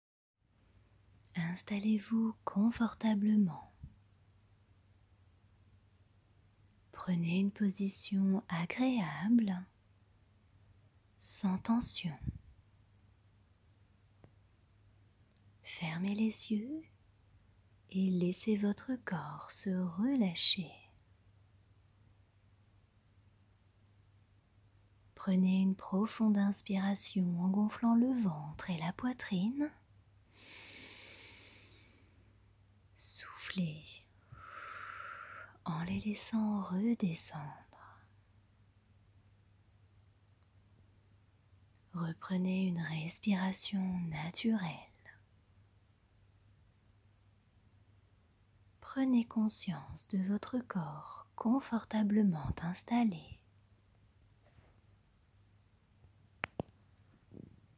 Extrait Vocal